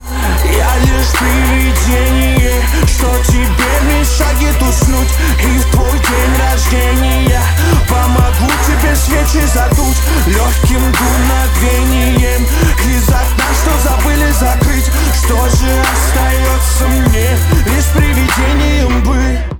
грустные
русский рэп
Bass